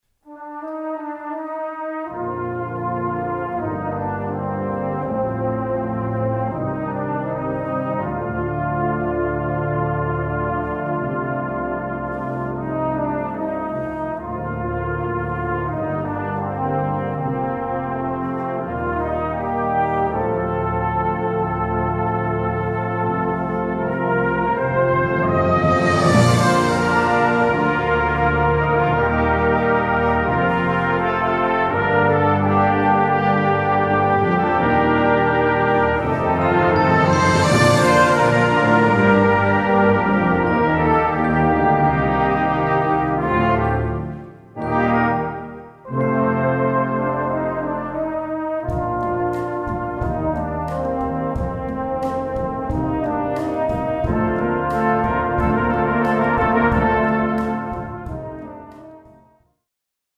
Gattung: Popmusik aus Frankreich
Besetzung: Blasorchester